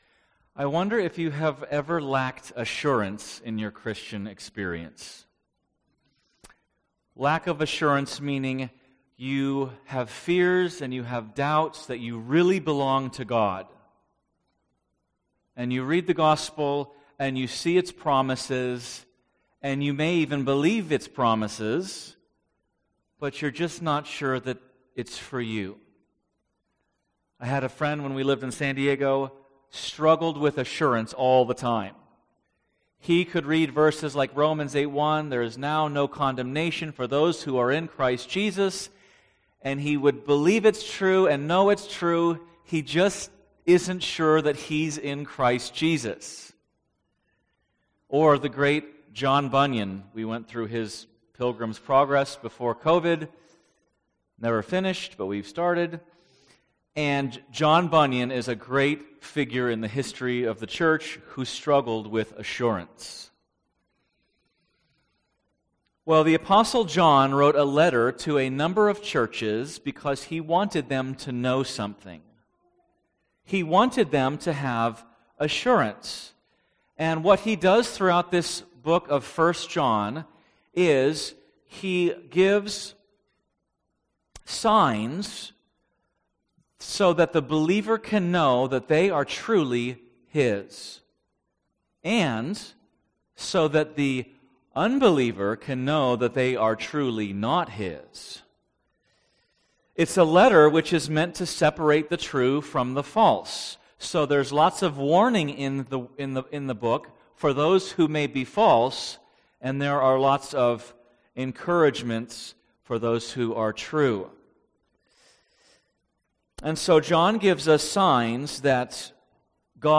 Podcast (heritage-valley-bible-church-sermons): Play in new window | Download